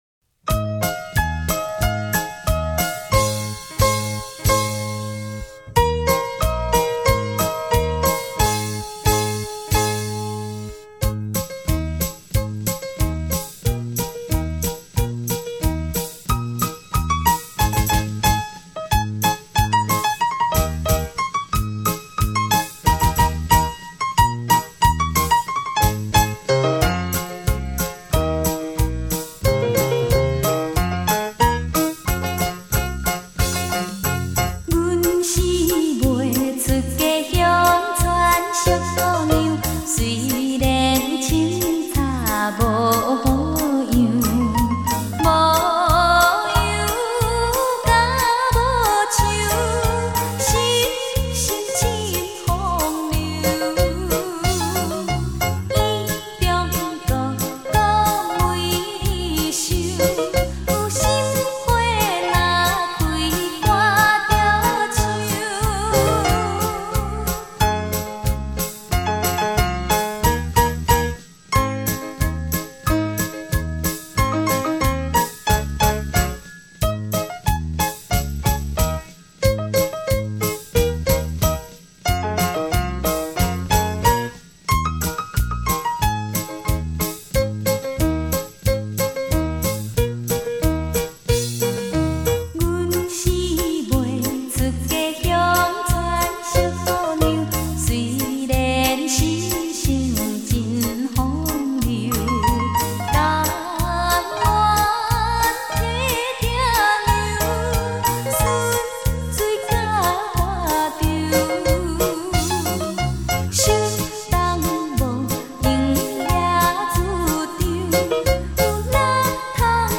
台语舞曲专辑
双钢琴Life演奏 精选熟悉好歌
吉鲁巴